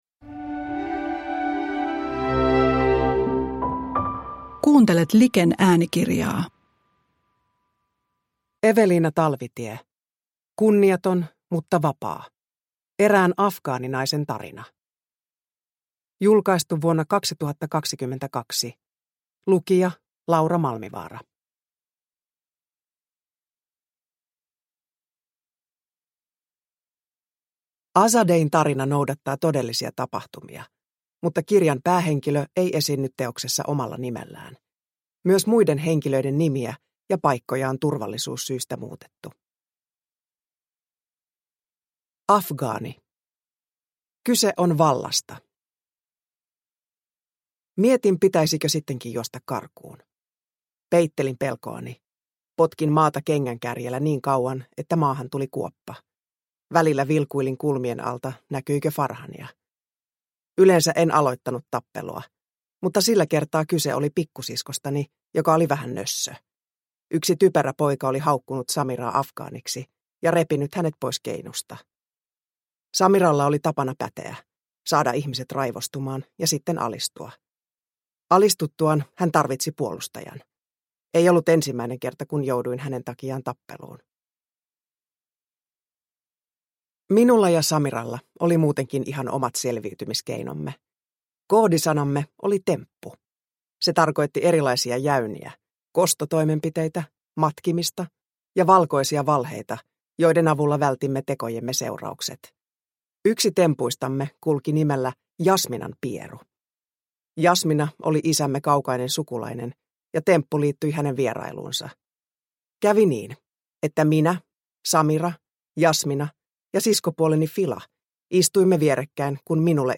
Kunniaton mutta vapaa – Ljudbok – Laddas ner
Uppläsare: Laura Malmivaara